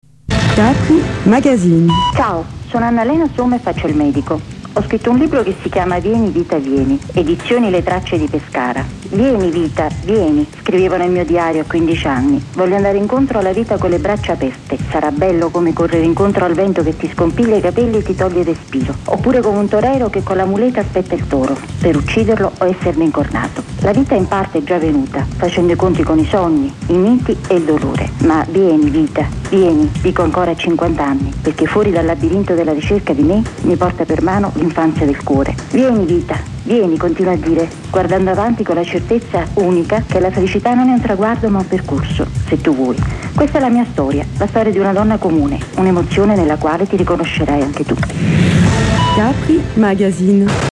Spot radio di "Vieni Vita Vieni" su "Radio Capri".
radio.capri.VieniVitaVieni.mp3